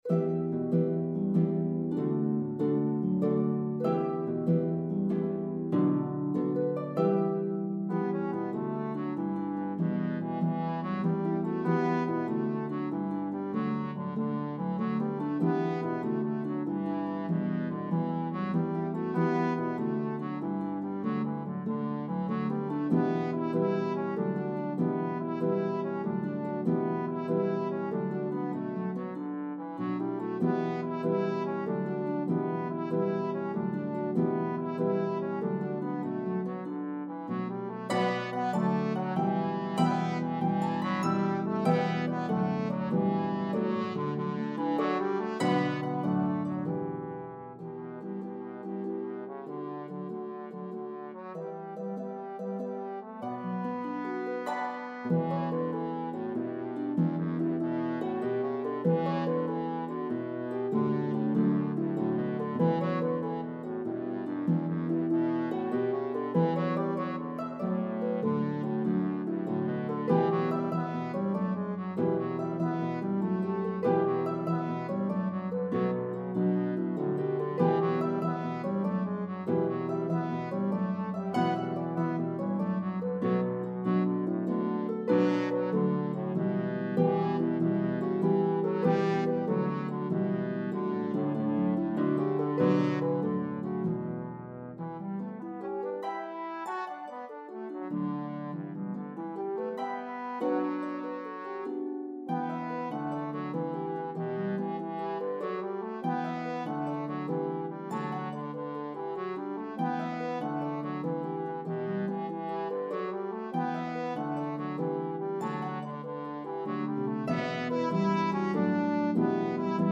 One of the most energetic Step Dances in Slip Jig form.